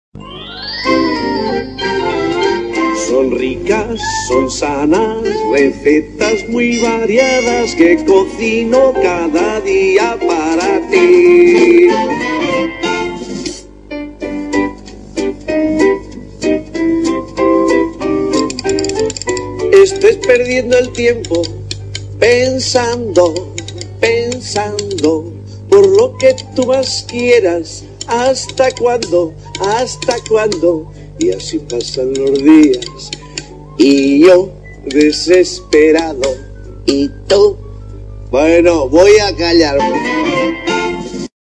TONOS DE MELODIAS DE PROGRAMAS DE TELEVISION